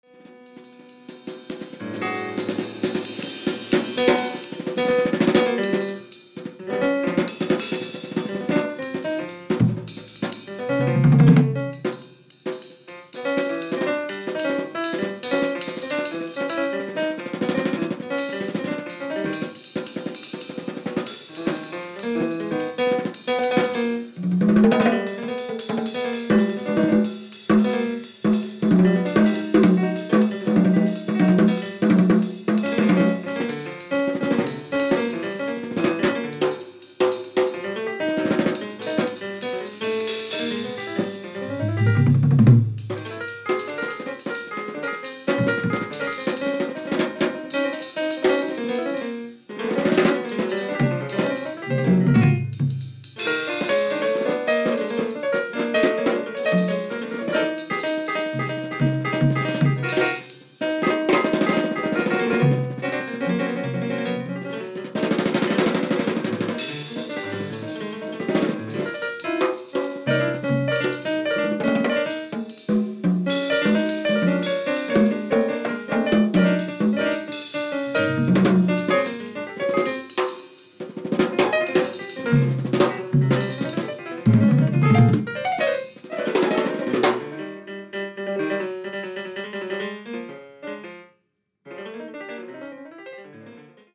Percussion
Piano